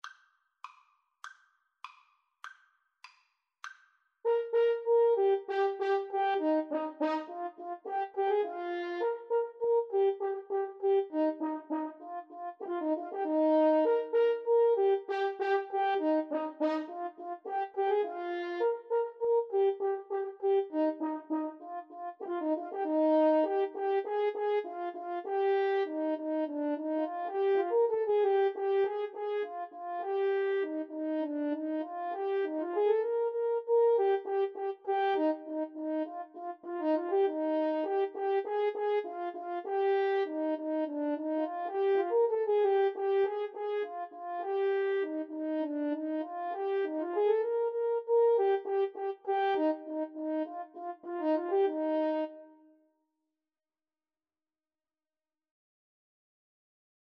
Allegro Molto (View more music marked Allegro)
2/4 (View more 2/4 Music)
French Horn Duet  (View more Easy French Horn Duet Music)
Classical (View more Classical French Horn Duet Music)